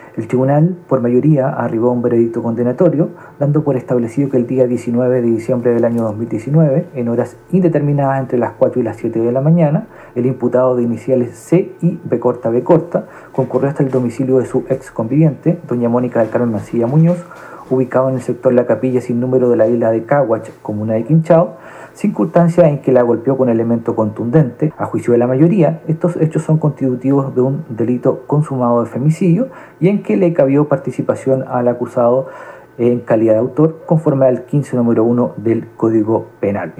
El juez del Tribunal Oral en Lo Penal de Castro, Rodrigo Alarcón, entregó algunos de los aspectos que se tomaron en cuenta para llegar a este fallo de mayoría.